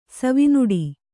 ♪ savi nuḍi